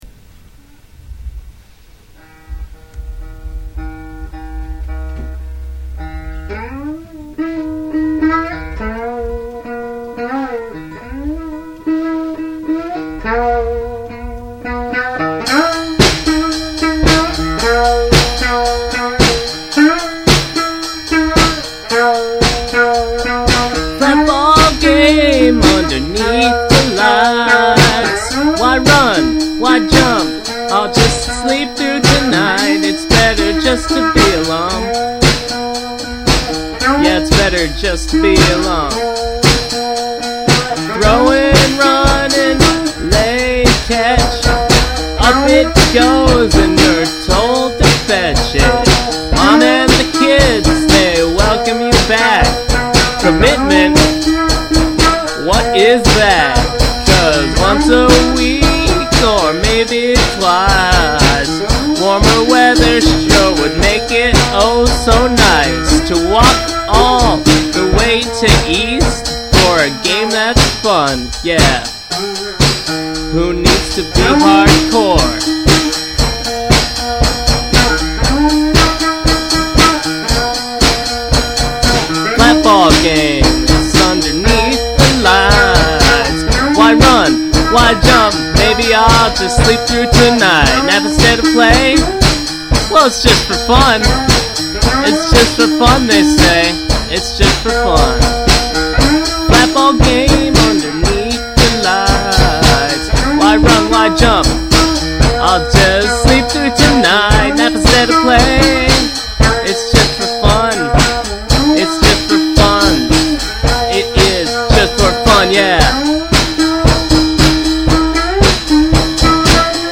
a decent little album featuring a number of fourtrack songs.